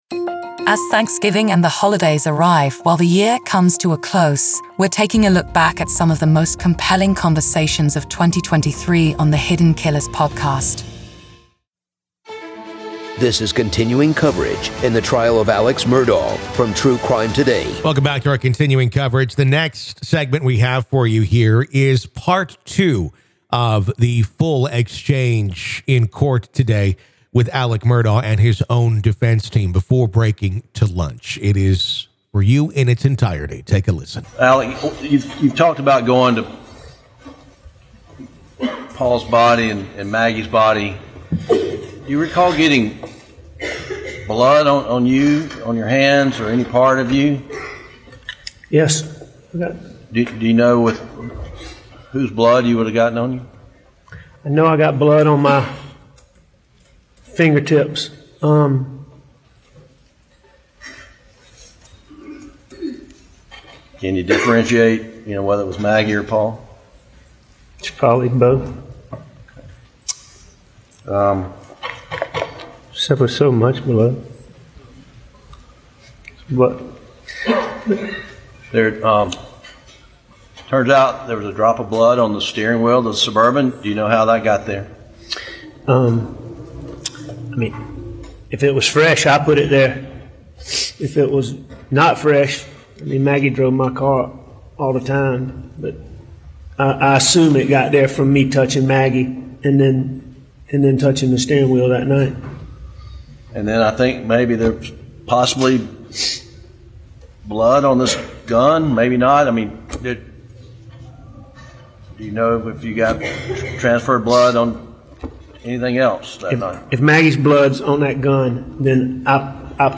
Join us as we revisit the most riveting cases, discussions, and courtroom recordings of 2023.